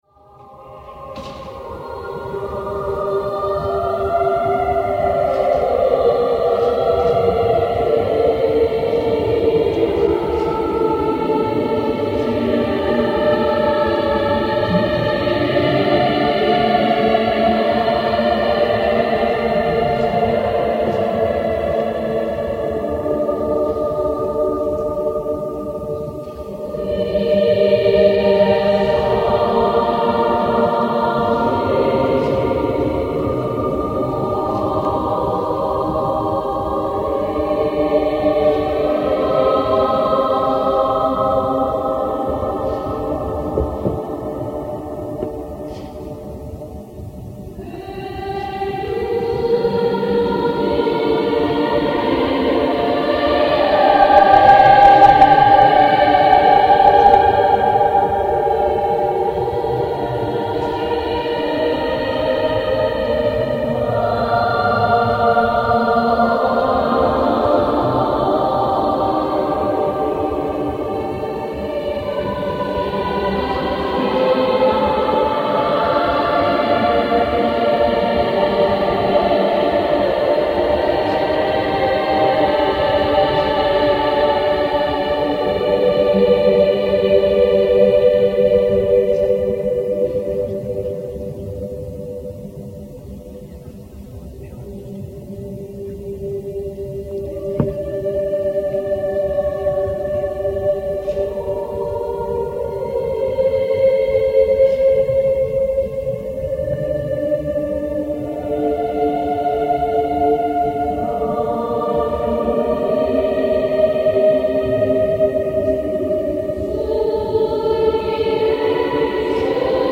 realizó una grabación analógica
de cinco cantos de la escolanía
al órgano de la basílica.